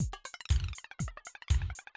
Soundfile 8: a quad (4-channel) file. Three channels have boring sounds.